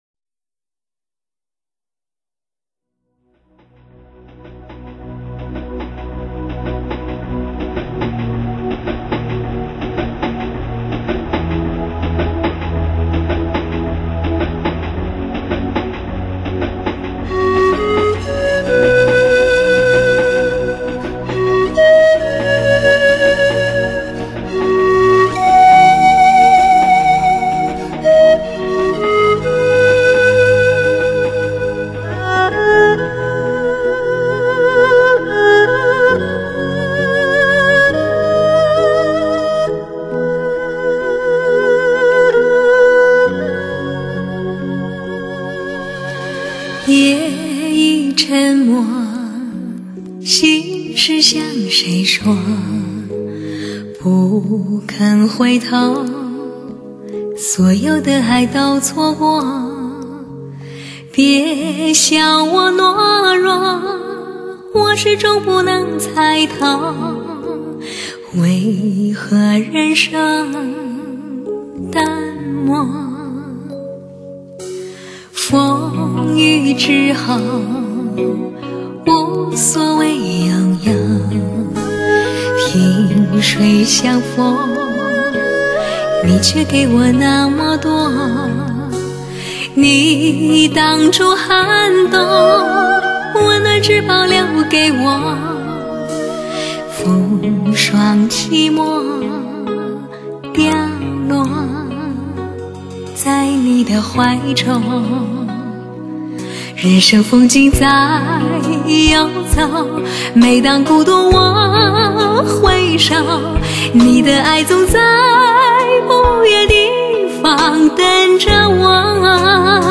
一种诗花的声音，拨动心灵深处的弦……。歌声的纯净，在这里悄然绽放。